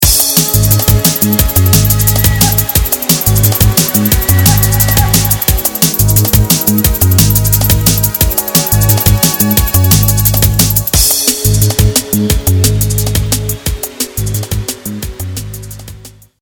Chilled dnb